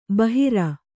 ♪ bahira